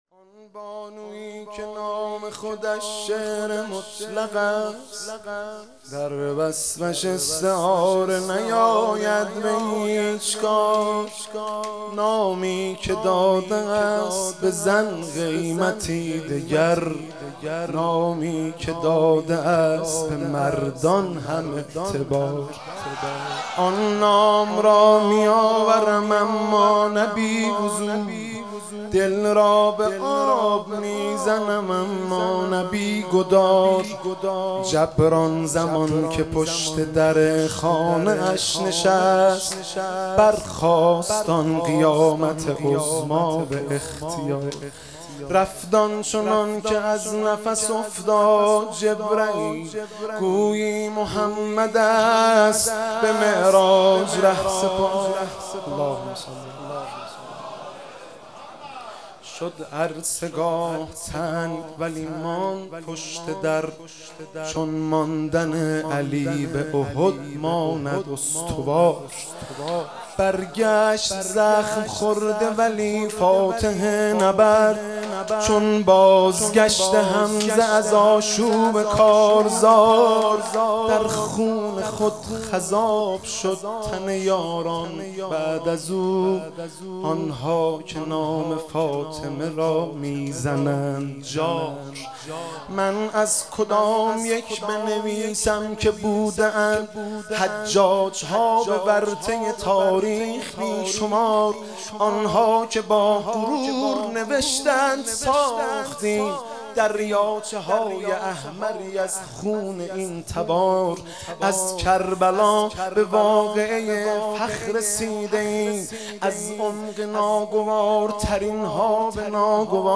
شب دوم فاطمیه 96 - مدح - بانویی که نام خودش شعر مطلق است